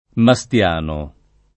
[ ma S t L# no ]